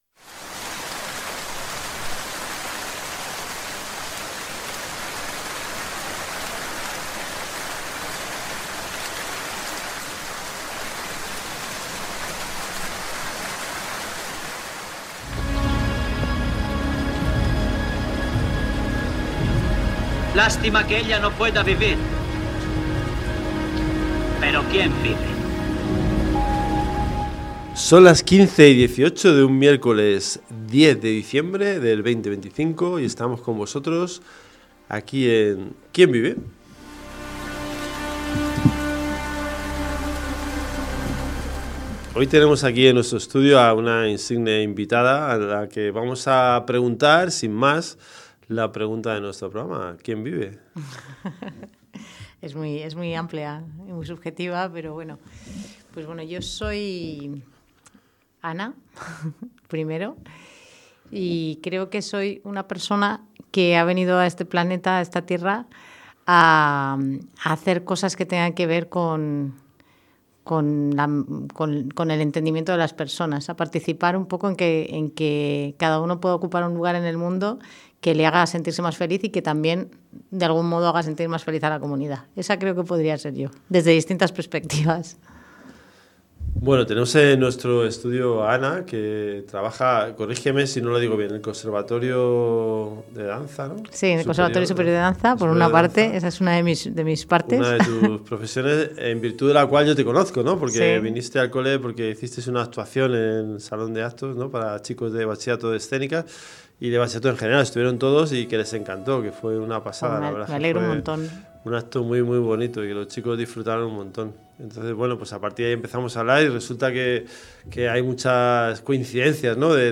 Una charla luminosa, valiente y profundamente humana.